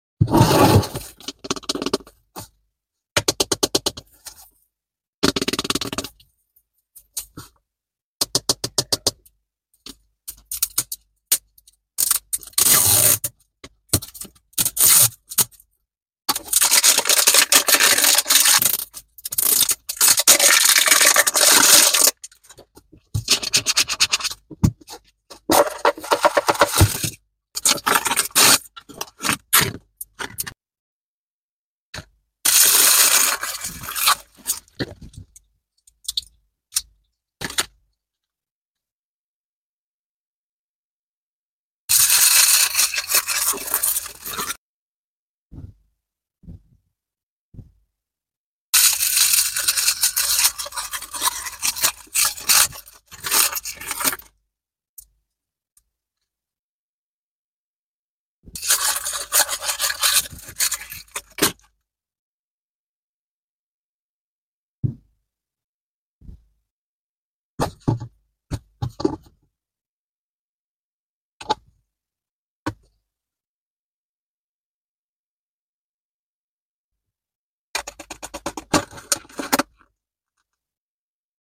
LOL Surprise Mini doll unboxing sound effects free download